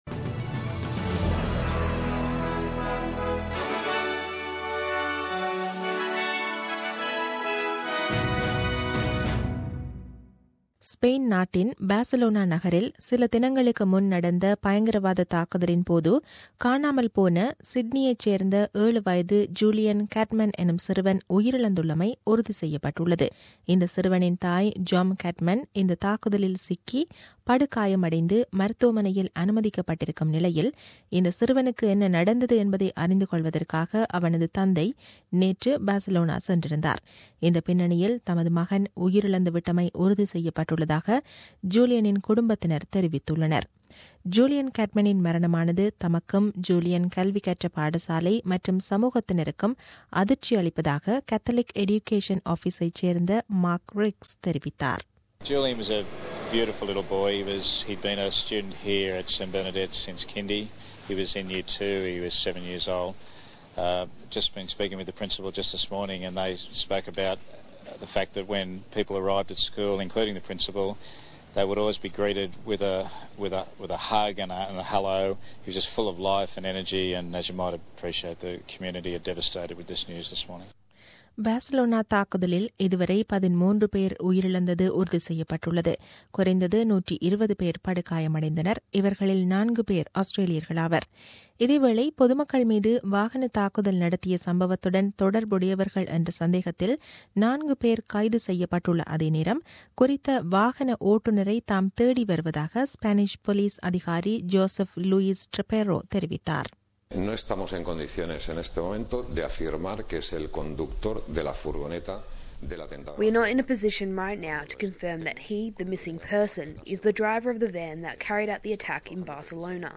The news bulletin aired on 21 Aug 2017 at 8pm.